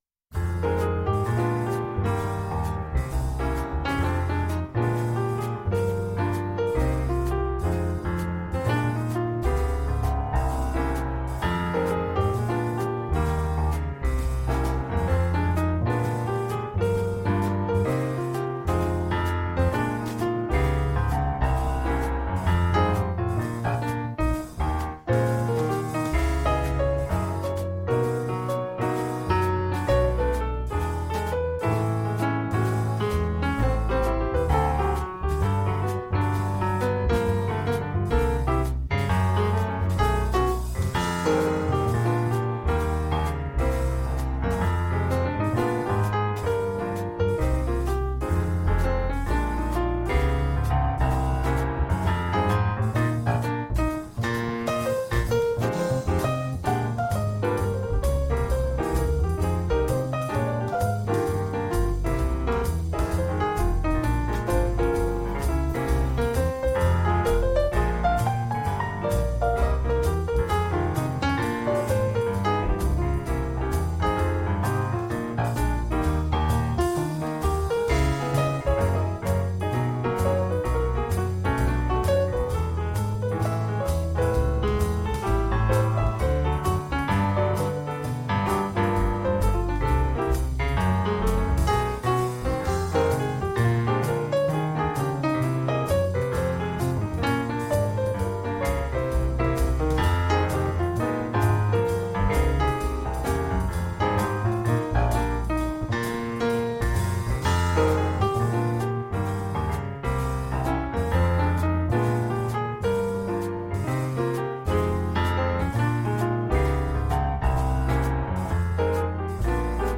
Toe teasin' jazz tunes for all occasions.